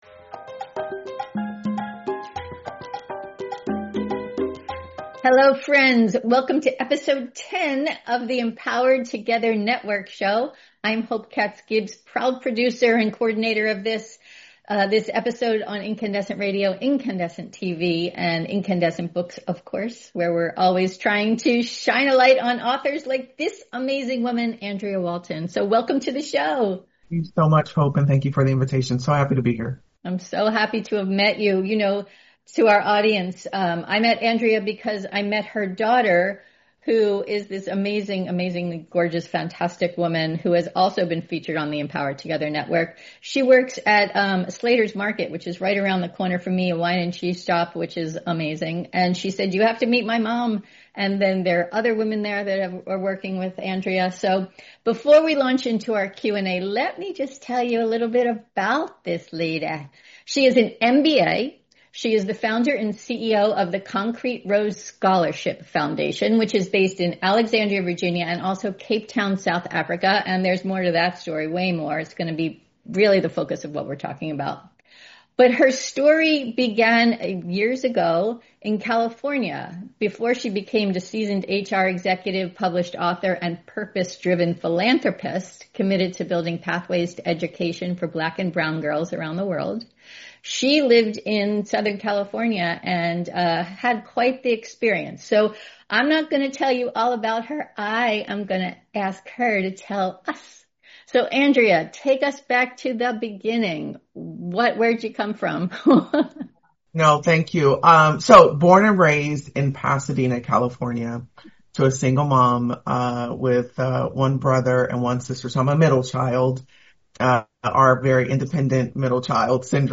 Before we jump into our Q&A, here’s a little about this truly amazing woman .